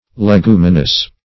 Leguminous \Le*gu"mi*nous\ (-m[i^]*n[u^]s), a. [Cf. F.